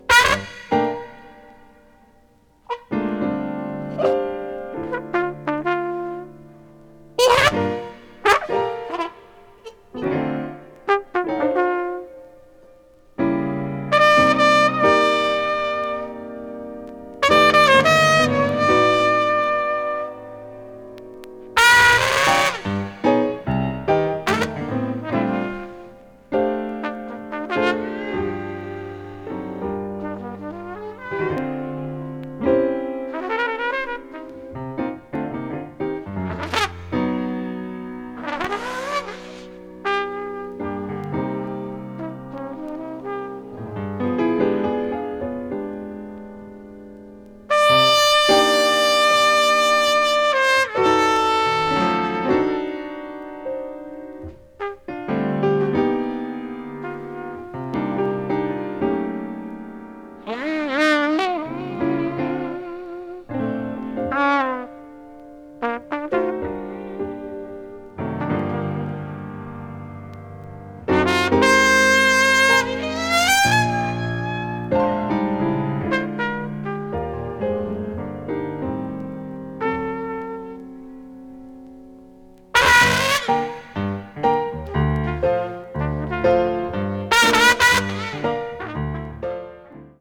avant-jazz   contemporary jazz   free jazz